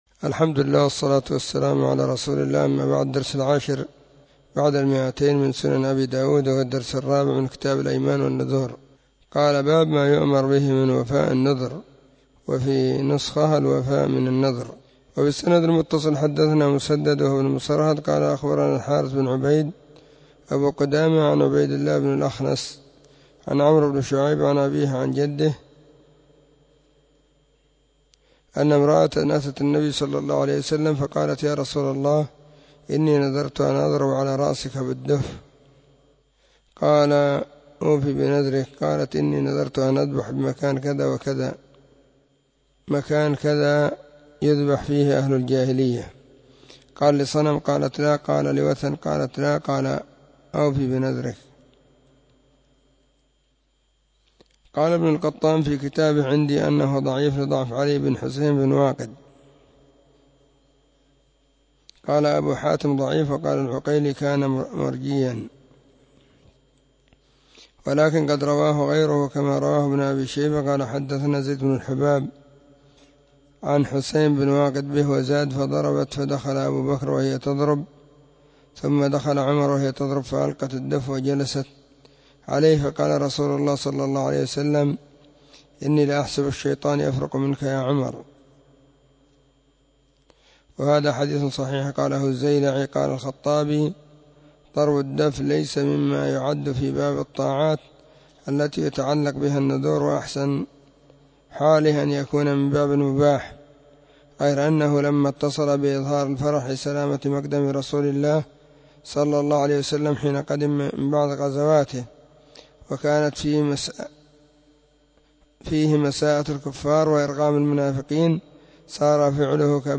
🕐 [بعد صلاة العصر في كل يوم الجمعة والسبت]